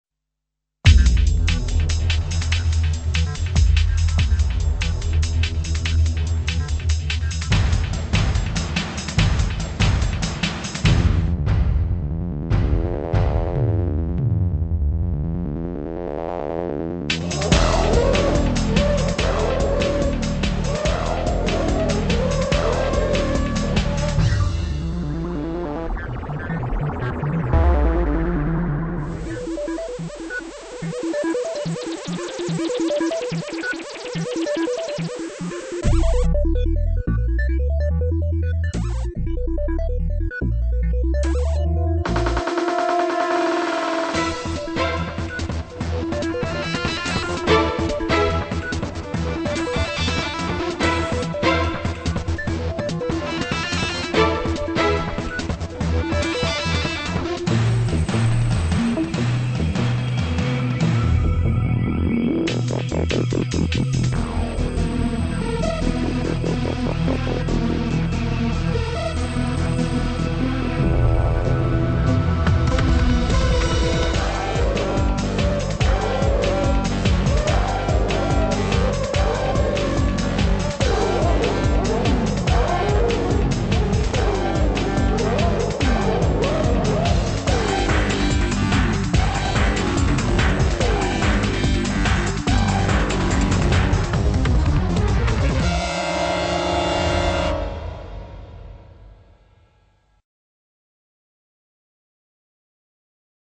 techno-y sounding bit